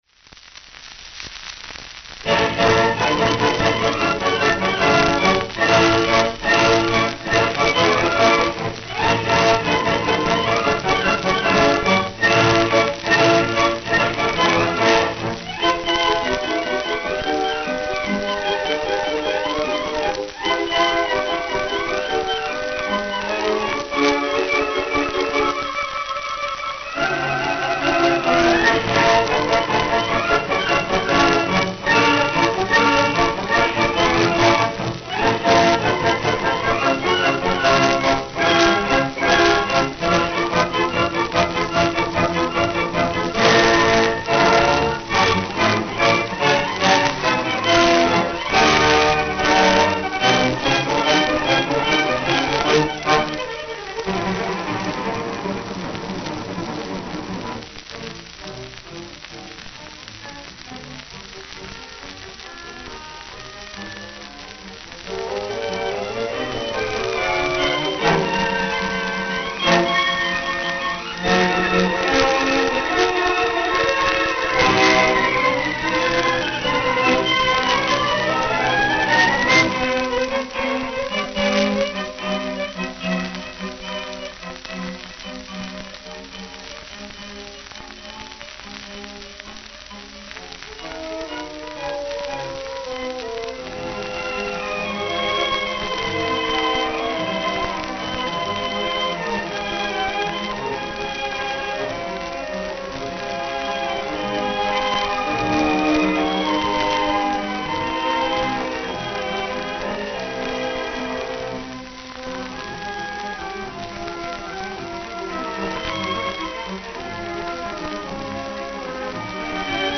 Victor Long-Playing Records
However, it failed in its mission to reduce surface noise and was actually a step back as far as slowing wear is concerned.
Chicago Symphony Orchestra (Conducted by Frederick Stock) Chicago Symphony Orchestra (Conducted by Frederick Stock)
Chicago, Illinois. Orchestra Hall. Chicago, Illinois. Orchestra Hall.